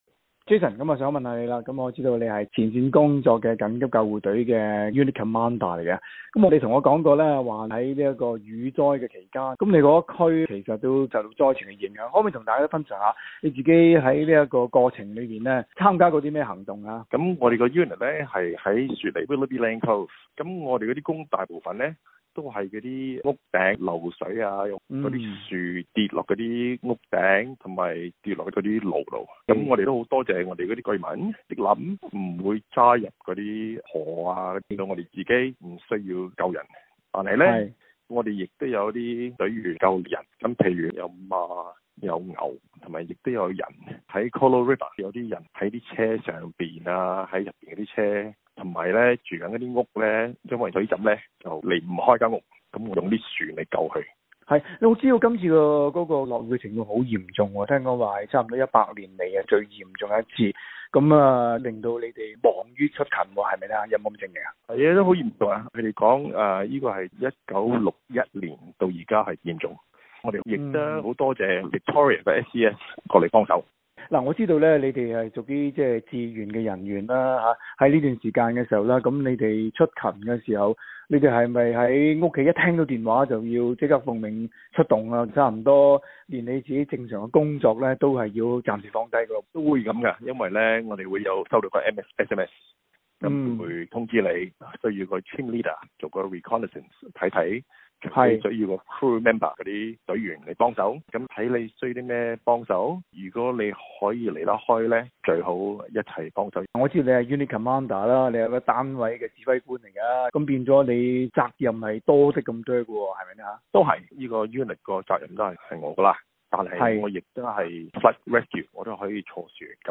今期專訪